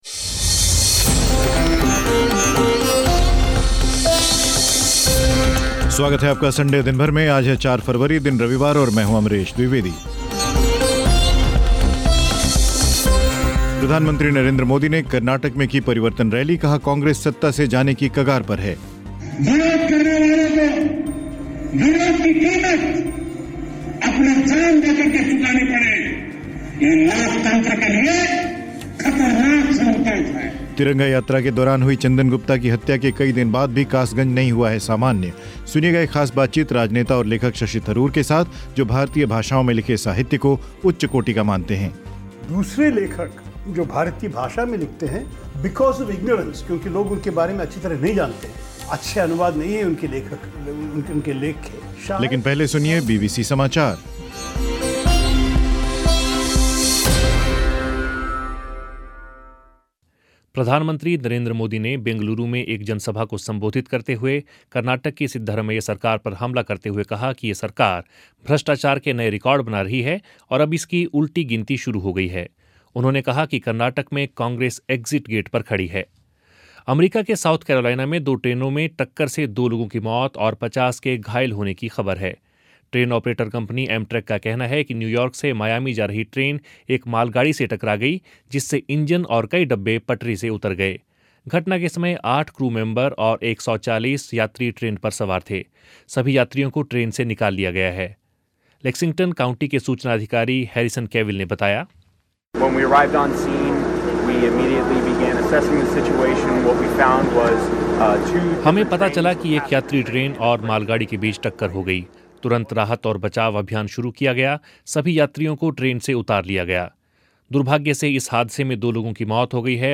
सुनिए एक ख़ास बातचीत राजनेता और लेखक शशि थरूर के साथ जो भारतीय भाषाओं में लिखे साहित्य को उच्च कोटि का मानते हैं